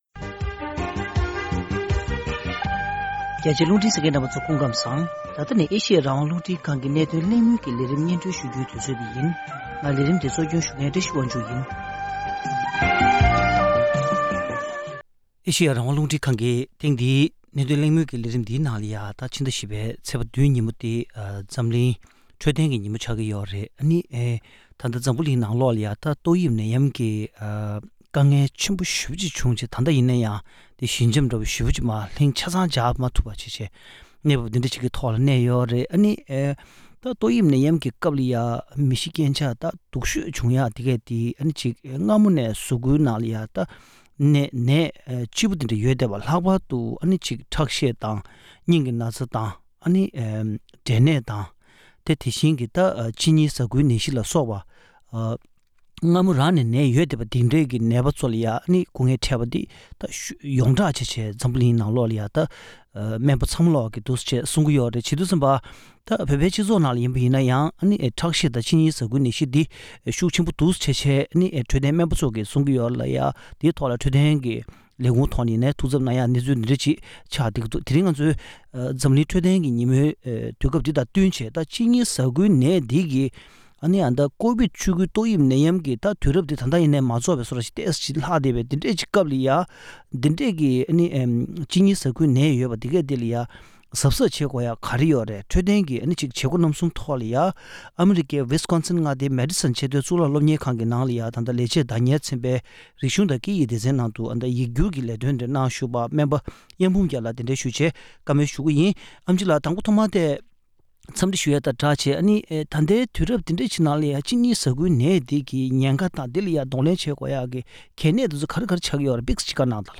༢༠༢༣ ཟླ་ ༤ ཚེས་ ༧ ཉིན་མོ་ནི་འཛམ་གླིང་འཕྲོད་བསྟེན་གྱི་ཉིན་མོ་འཁེལ་བ་ལྟར། གཅིན་སྙི་ཟ་ཁུའི་ནད་གཞི་དང་འབྲེལ་བའི་གོ་རྟོགས་སྤེལ་སླད་གླེང་མོལ་ཞུས་པ།